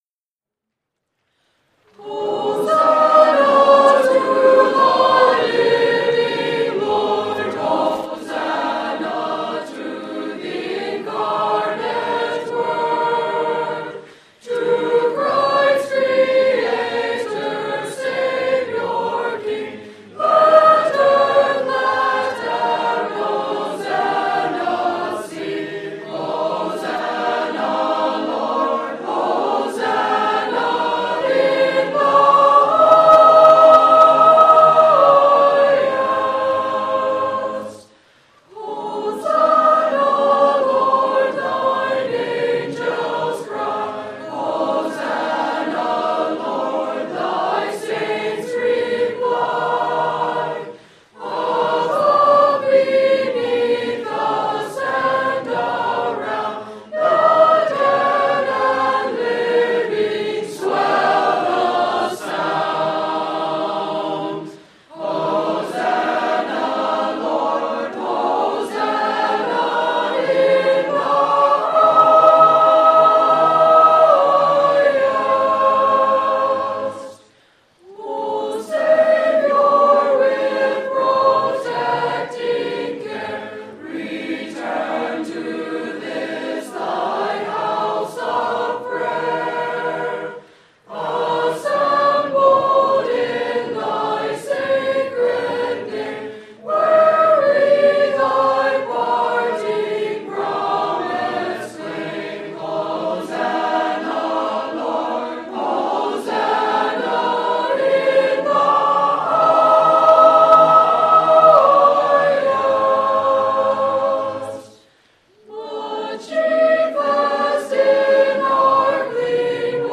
Series: Youth Inspiration Day 2020